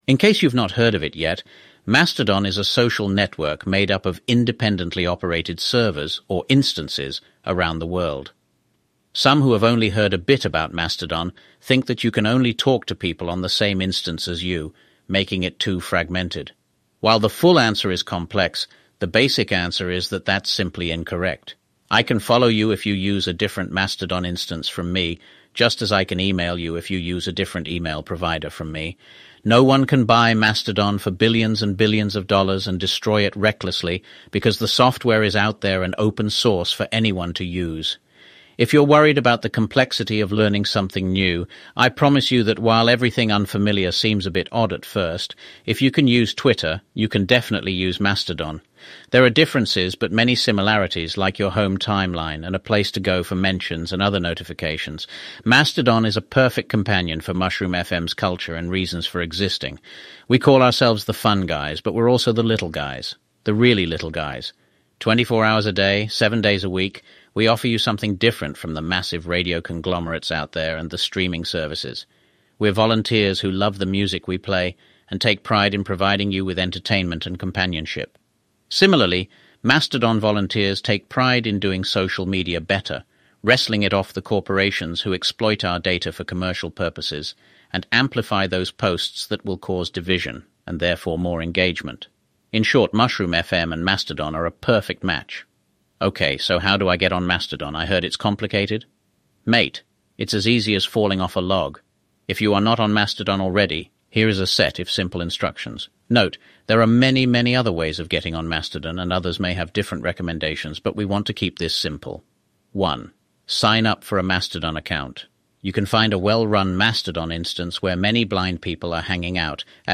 I fed Eleven labs a bunch of samples of my voice and told it to create a text to speech engine based on them. I don't think this sounds the remotest bit like me.